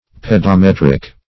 Search Result for " pedometric" : The Collaborative International Dictionary of English v.0.48: Pedometric \Ped`o*met"ric\, Pedometrical \Ped`o*met"ric*al\, a. Pertaining to, or measured by, a pedometer.